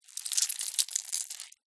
Звук ловли пиявки